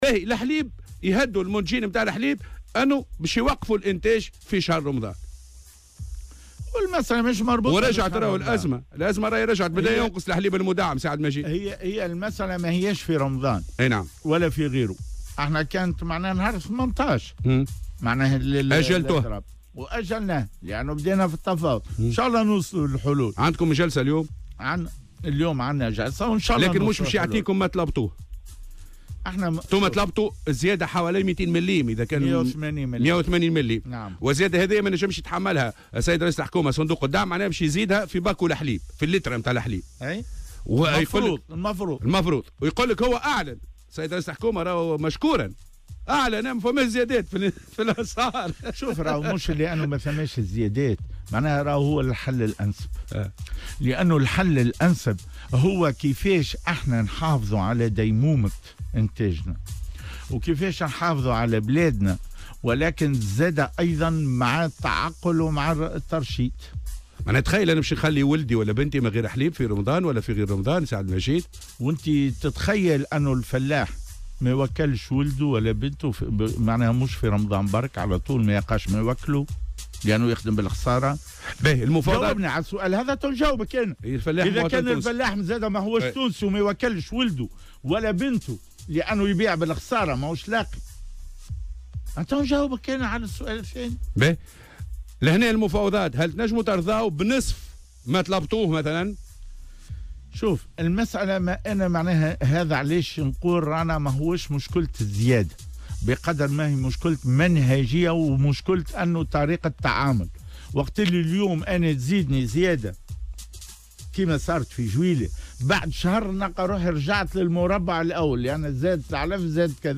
مداخلة له اليوم في برنامج "صباح الورد" على "الجوهرة أف أم"